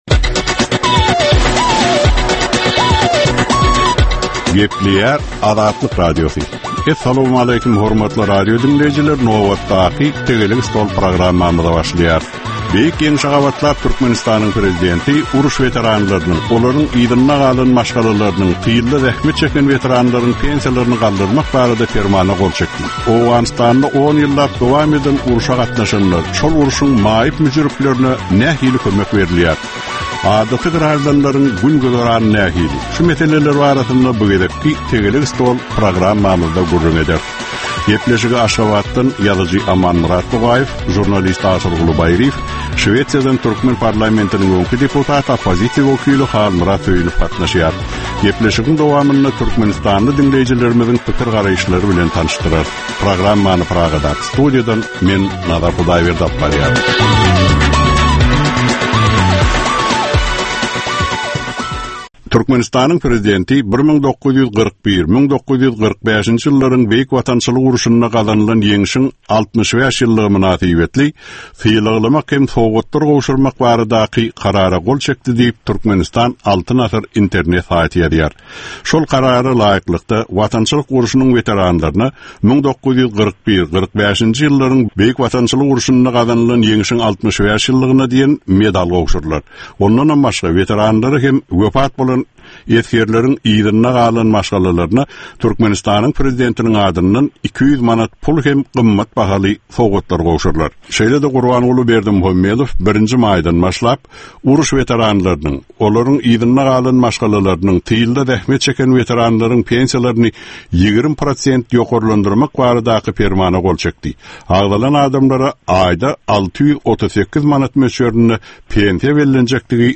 Jemgyýetçilik durmuşynda bolan ýa-da bolup duran soňky möhum wakalara ýa-da problemalara bagyşlanylyp taýýarlanylýan ýörite Tegelek stol diskussiýasy. 30 minutlyk bu gepleşikde syýasatçylar, analitikler we synçylar anyk meseleler boýunça öz garaýyşlaryny we tekliplerini orta atýarlar.